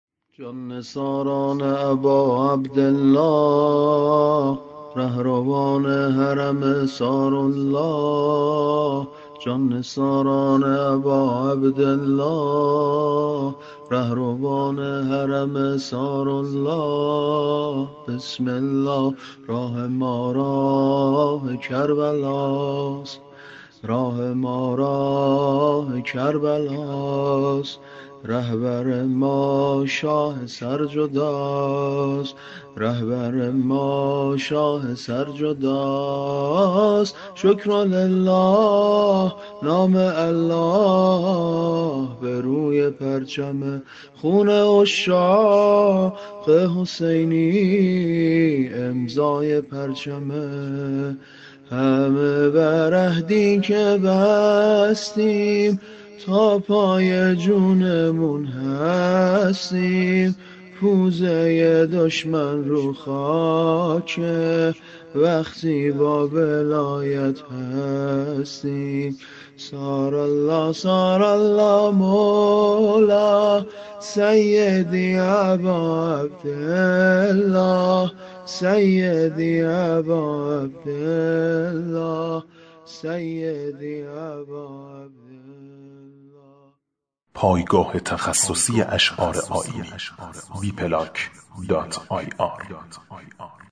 شور - - -